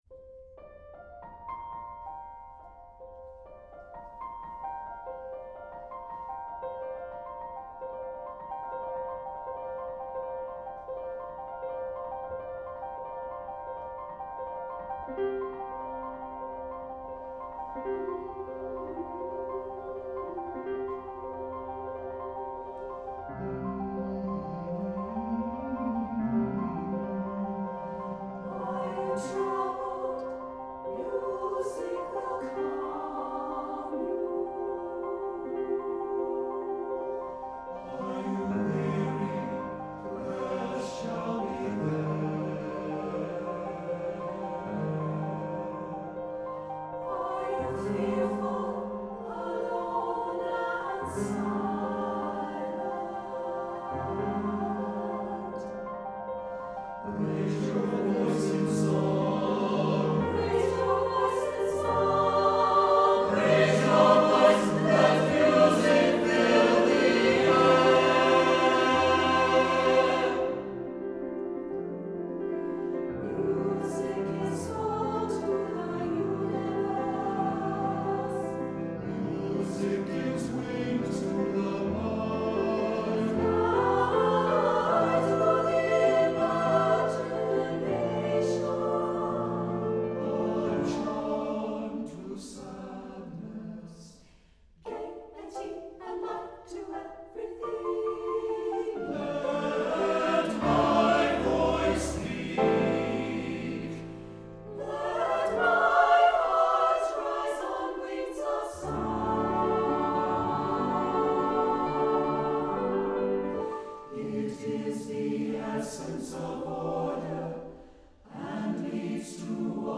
for SATB Chorus and Piano (2003)